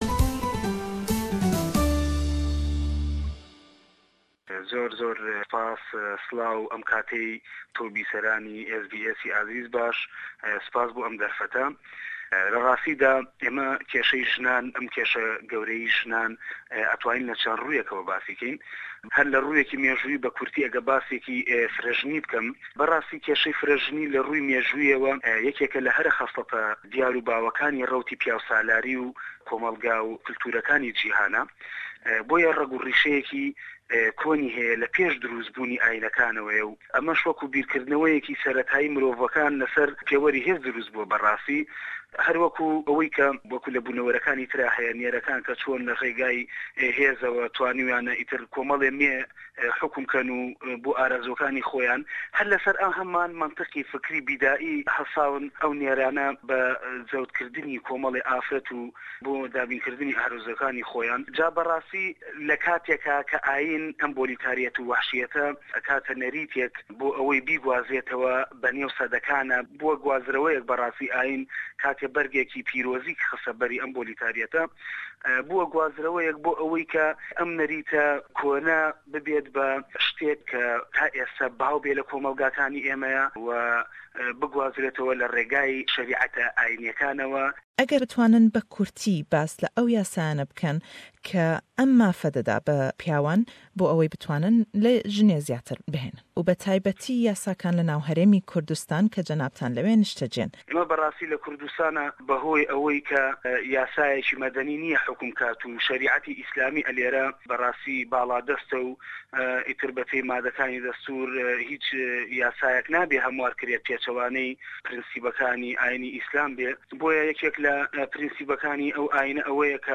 Le em hevpeyvîne da le gell nûser û çalakwanî mafî mirov